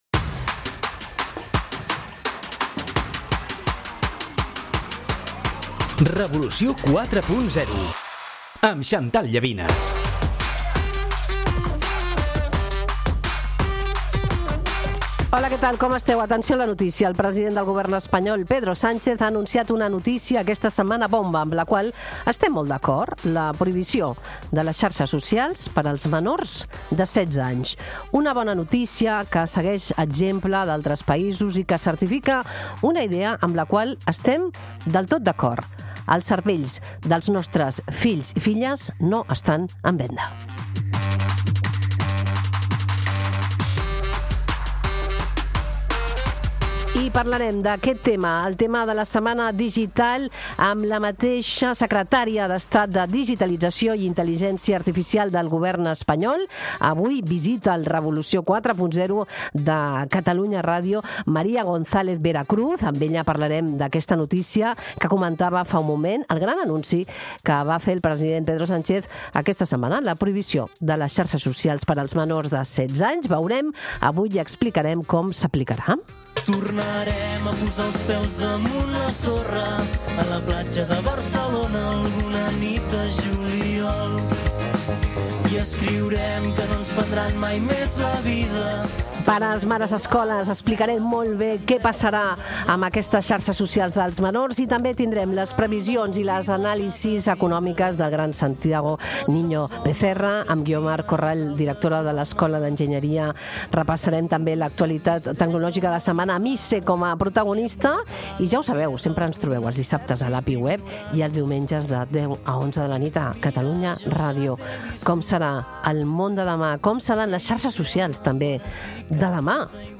entrevista María González Veracruz, secretària d'estat de Digitalització i Intel·ligència Artificial, coincidint amb l'anunci del govern de prohibir les xarxes socials als menors de 16 anys. González Veracruz explica la mesura i analitza el moment del sector tecnològic i de l'economia digital, que ja genera prop d'un 25% del PIB espanyol. També defensa un model tecnooptimista basat en innovació, regulació i consciència social, i posa el focus en la sobirania digital a escala espanyola i europea, així com en les inversions en digitalització, amb gairebé 1.000 milions d'euros destinats a Catalunya des del 2020.